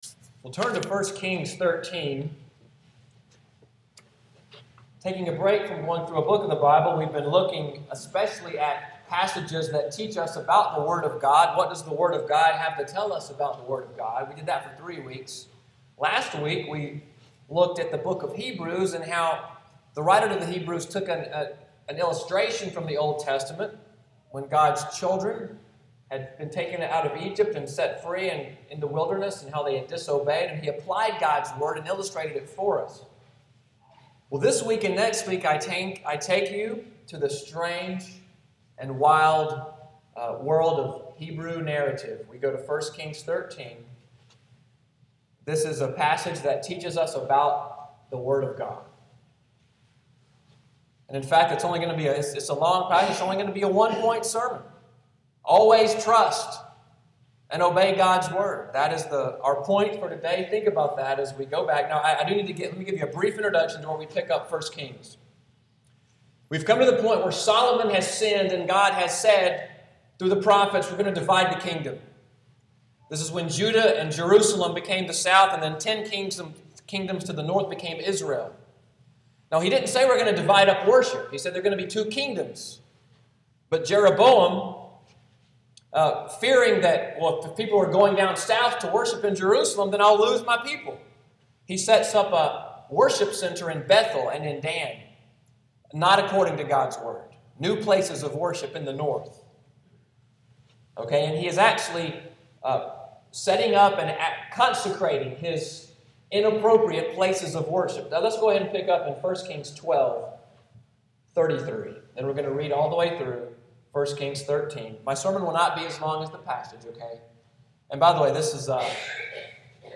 Sunday’s sermon, “The Word of the Lord“*, July 6, 2014.